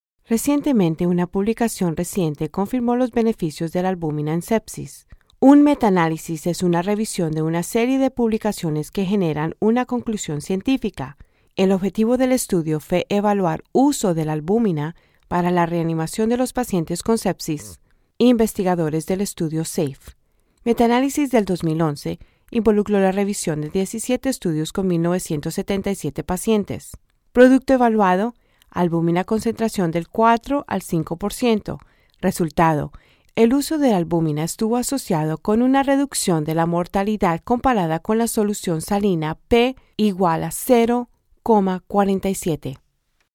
GP LATIN SPANISH COLOMBIA
Voice Sample: Voice Sample
We use Neumann microphones, Apogee preamps and ProTools HD digital audio workstations for a warm, clean signal path.
GP_Latin_Spanish_Colombia_Female_VoiceSample.mp3